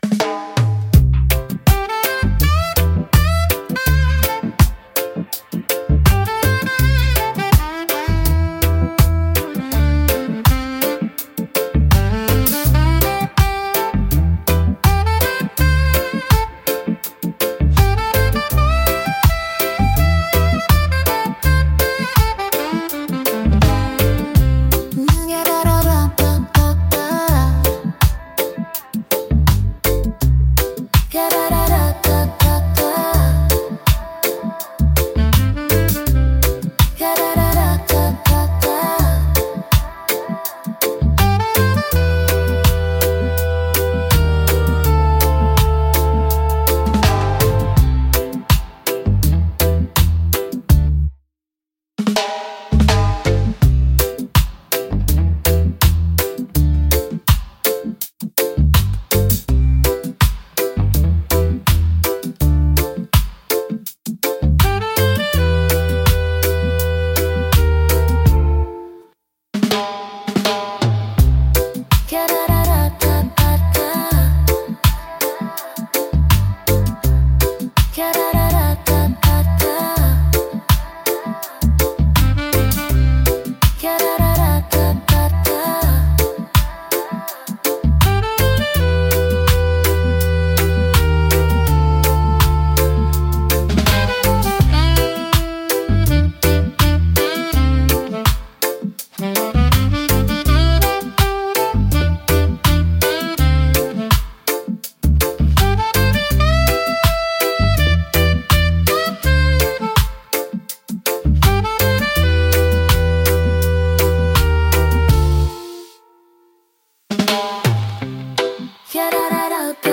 レゲエ特有のベースラインとドラムパターンが心地よいグルーヴを生み出します。
ナチュラルで陽気な雰囲気を演出し、聴く人に穏やかで楽しい気分をもたらします。